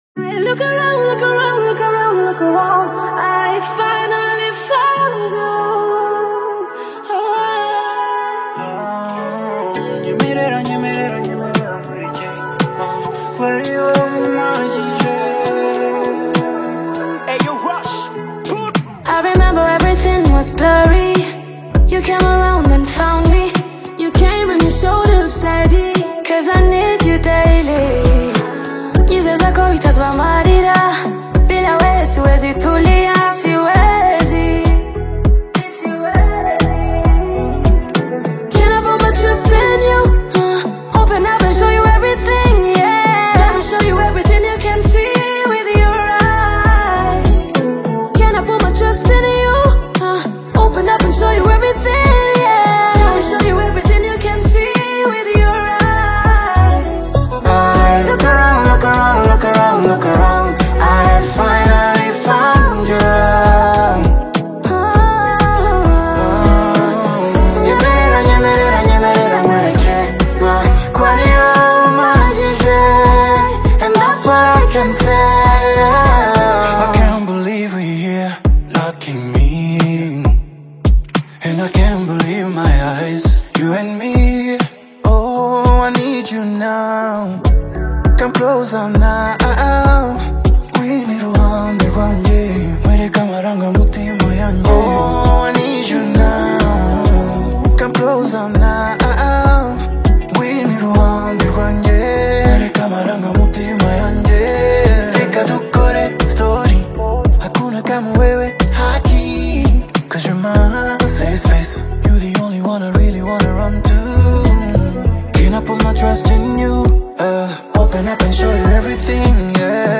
Afro-Pop/urban single
soulful vocals